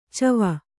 ♪ cava